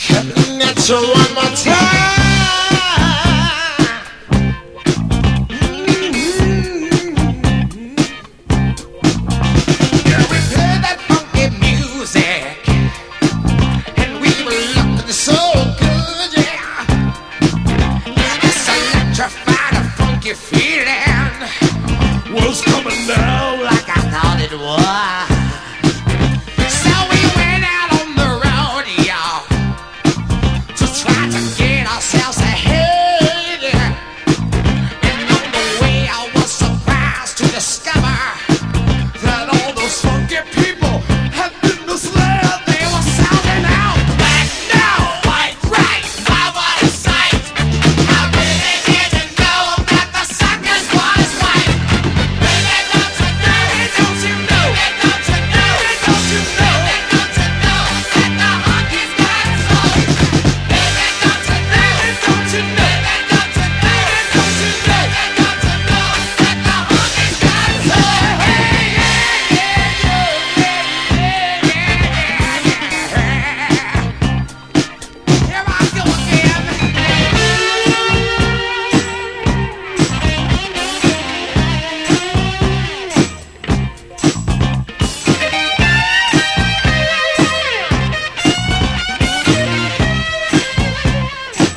ブリージンなミディアム・メロウ・グルーヴ
灼熱のファンキー・グルーヴ